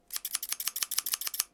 Tijera de peluquero cortando pelo
Sonidos: Acciones humanas